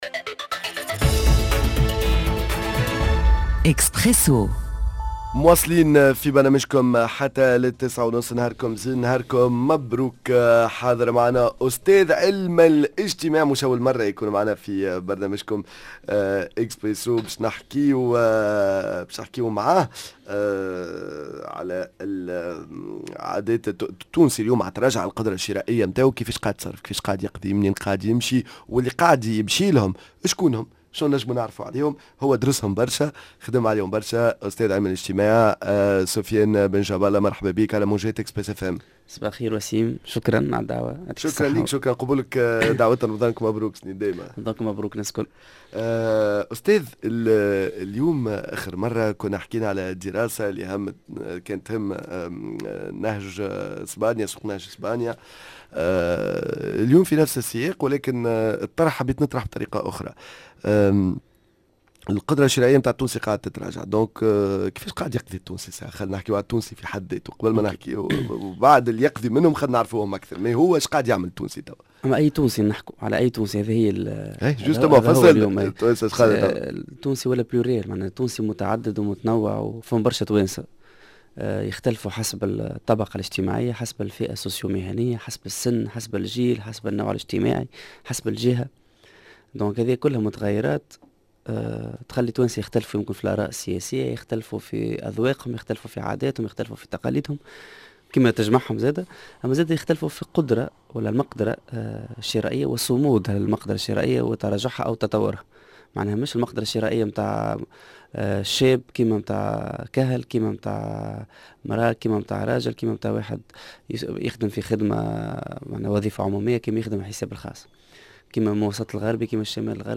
#L’interview